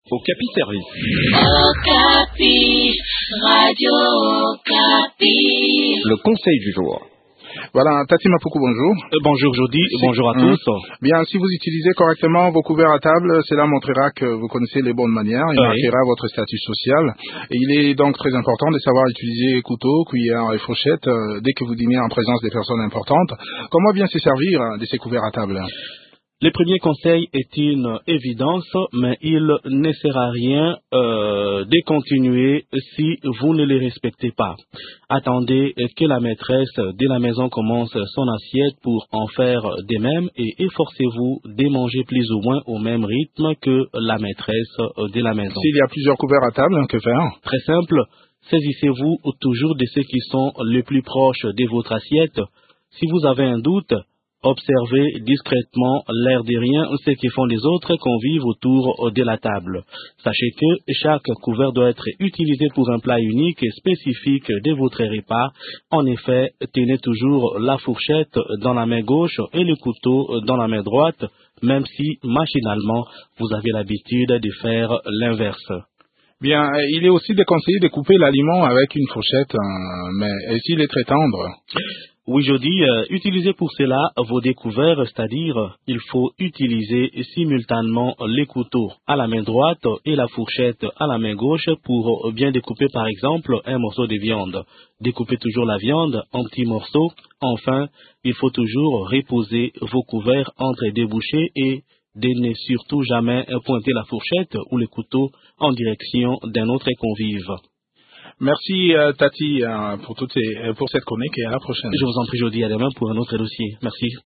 Découvrez des conseils pratiques dans cette chronique